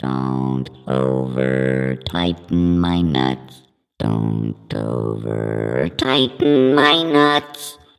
描述：一个可循环的机器人幽默片。
Tag: 120 bpm Electronic Loops Vocal Loops 1.35 MB wav Key : Unknown